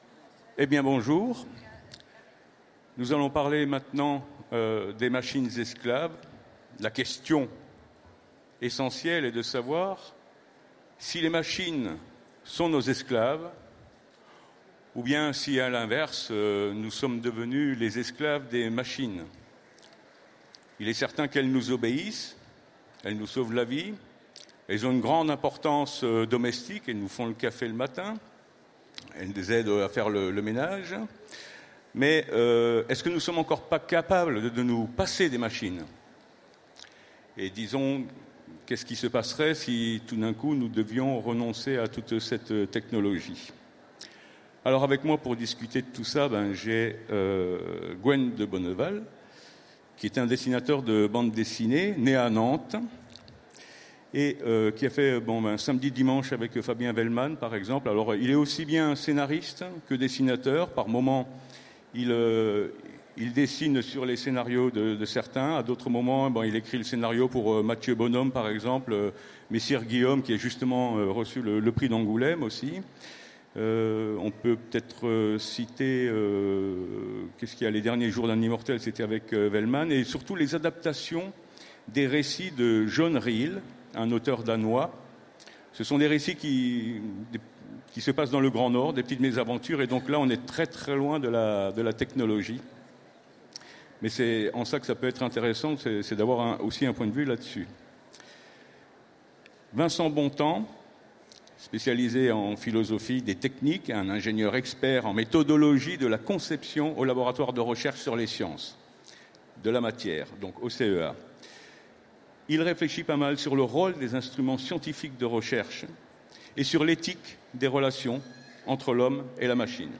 Utopiales 2016 : Conférence Les machines sont-elles nos esclaves ou...?